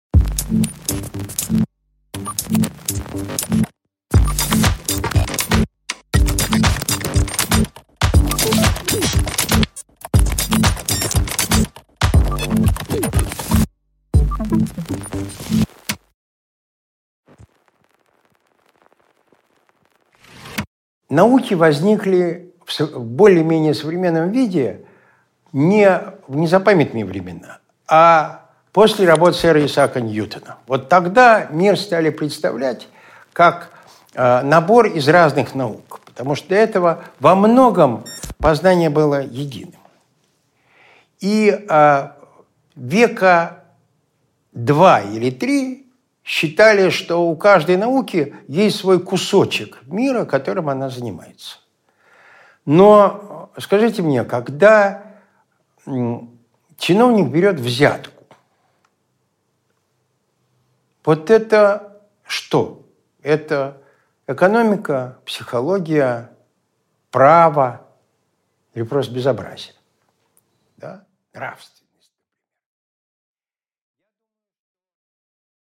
Аудиокнига Наука жить в мире | Библиотека аудиокниг